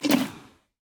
Minecraft Version Minecraft Version snapshot Latest Release | Latest Snapshot snapshot / assets / minecraft / sounds / mob / mooshroom / eat4.ogg Compare With Compare With Latest Release | Latest Snapshot
eat4.ogg